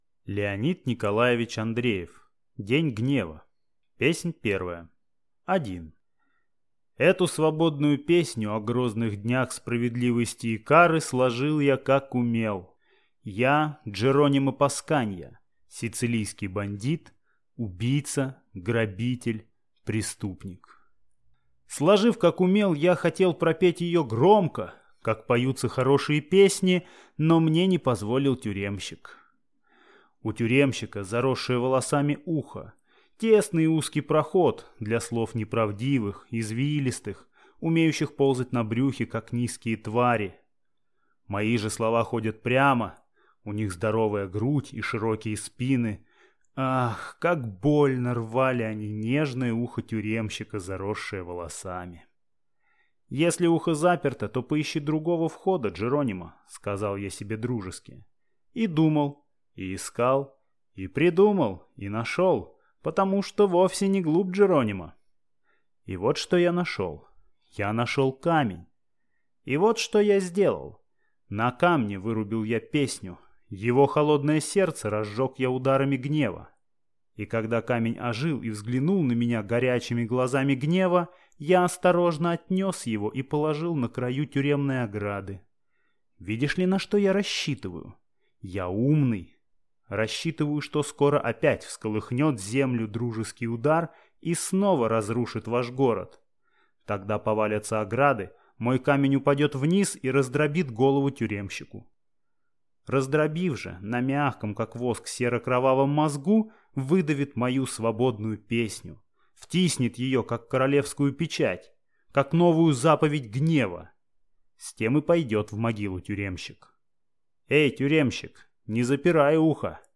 Аудиокнига День гнева | Библиотека аудиокниг